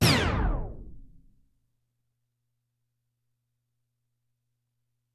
tape_slow4
252basics halt porta roland screech slow stop tape sound effect free sound royalty free Memes